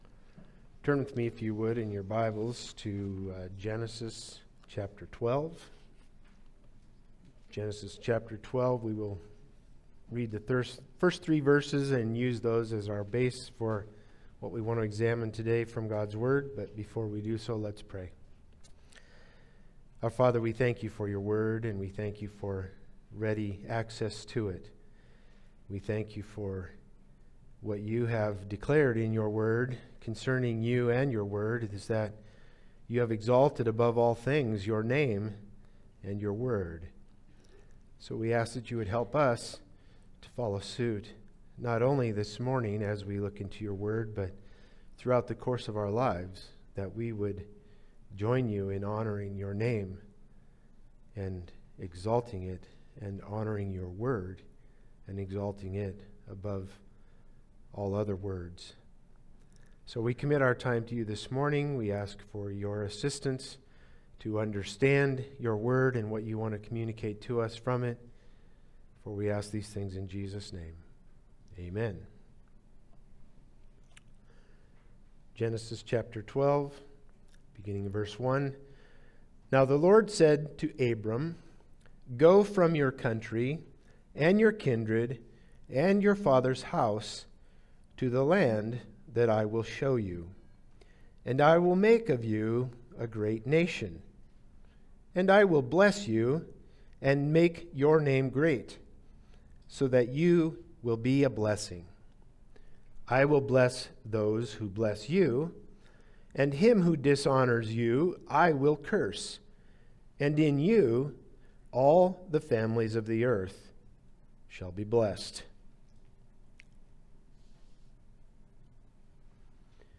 Passage: Genesis 12:1-3 Service Type: Sunday Service « It Is Finished Who is This King of Glory?